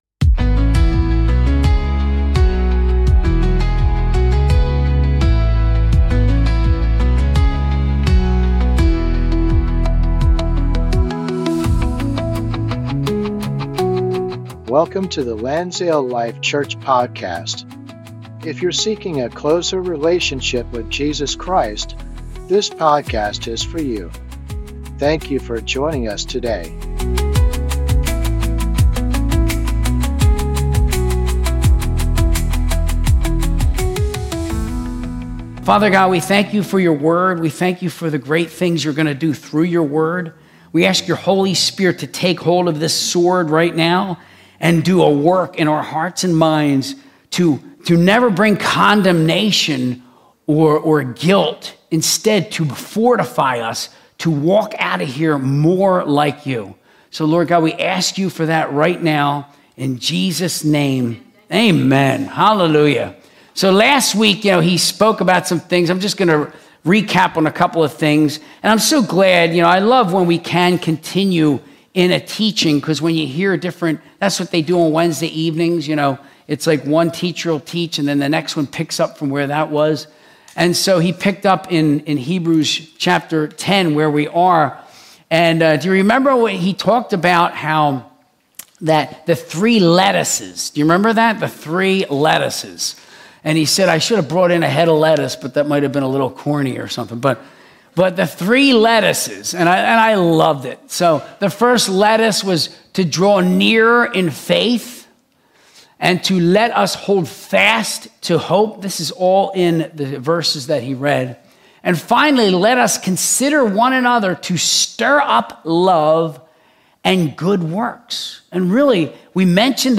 Sunday Service - 2025-10-26